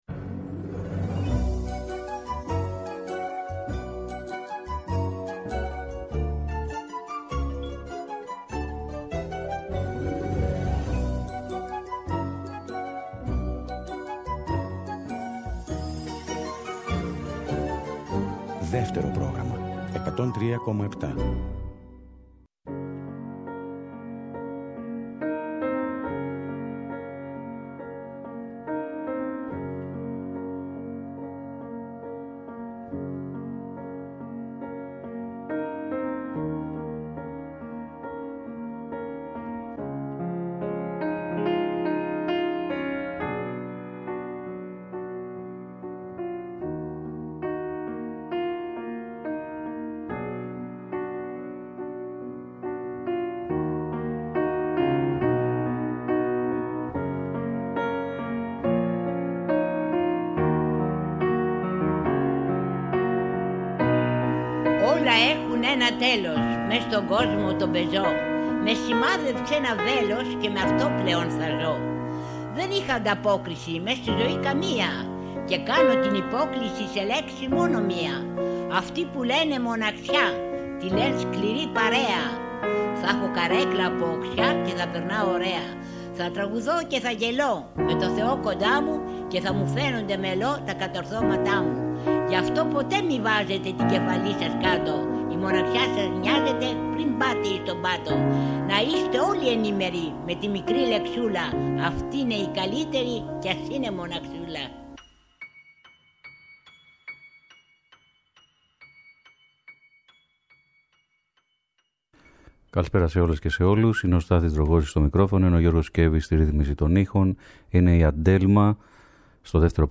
Μαζί με την περιγραφή του Θεοδωράκη – όπως τον γνώρισε ο τραγουδοποιός – θα ακουστούν τραγούδια από αυτούς τους κύκλους τραγουδιών και από άλλα αγαπημένα του τραγουδοποιού με μερικά ενδιαφέροντα βιογραφικά στοιχεία..